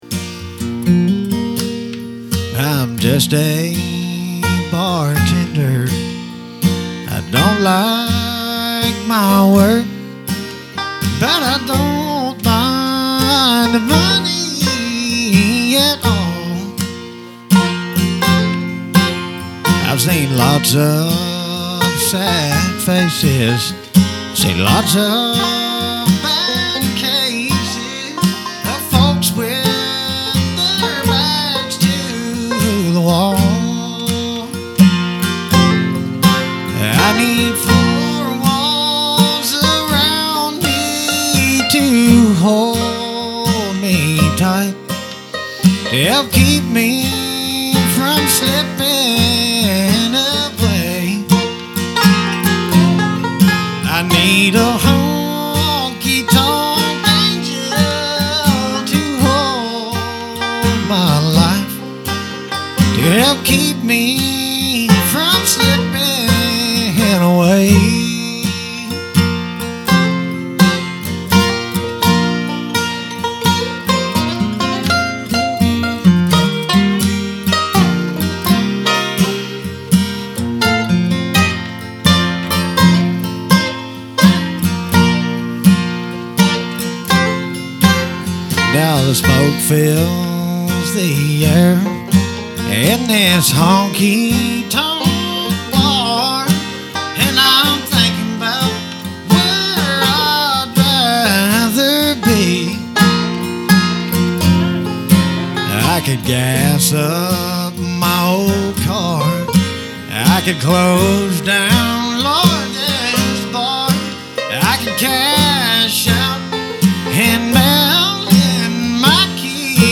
Storytelling, plus music!